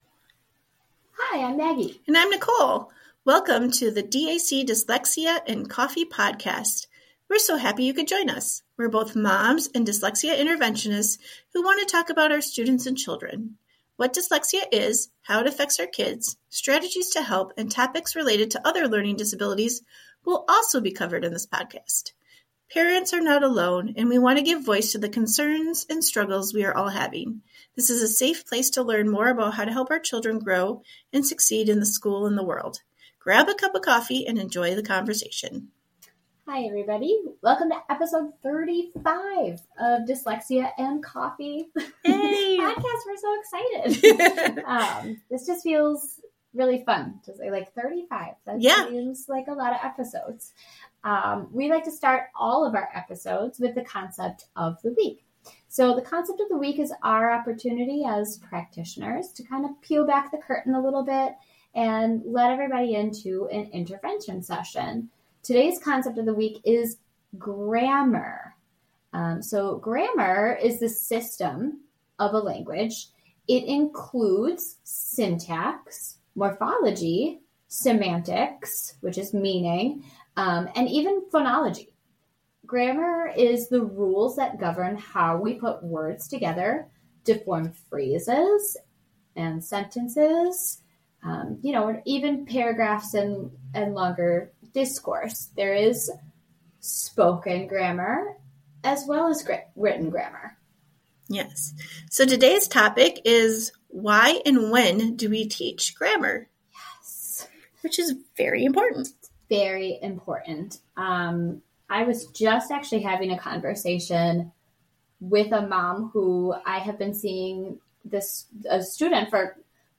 We are both moms and dyslexia interventionists who want to talk about our students and children.